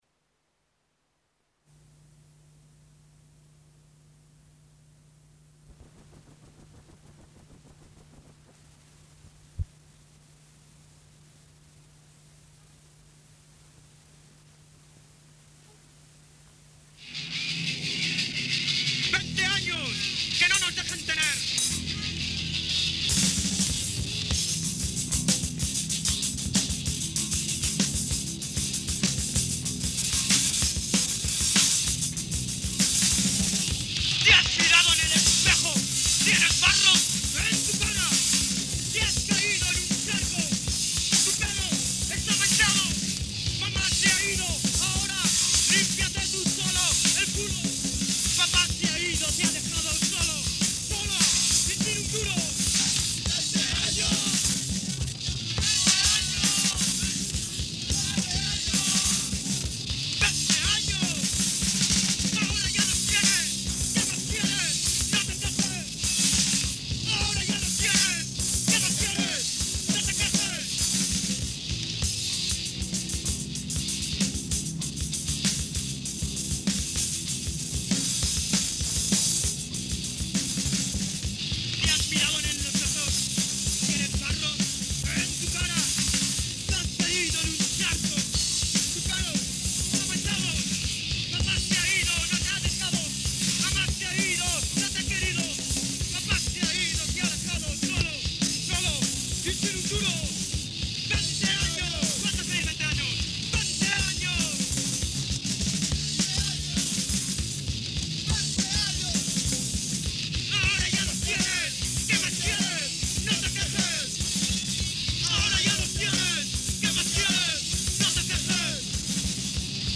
Un fichier MP3 correspondant à une face de cassette audio. La face B de cette cassette était vierge.